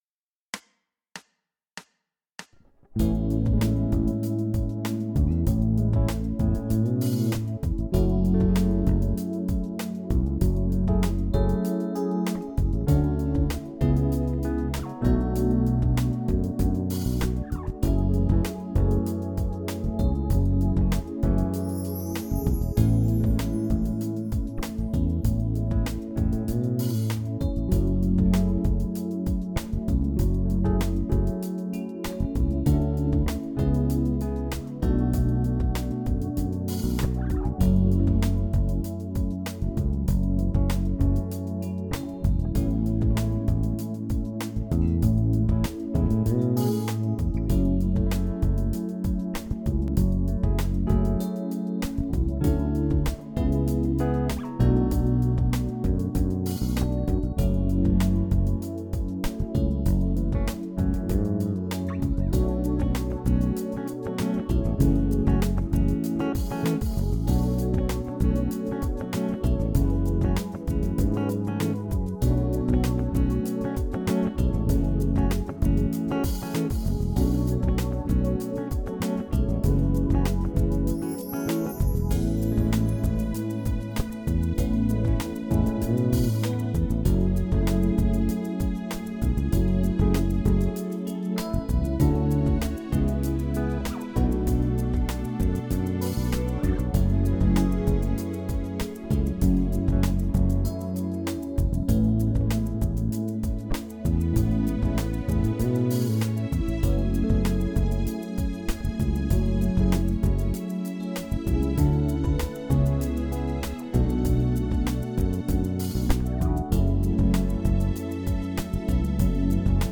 Jam Track
Jam track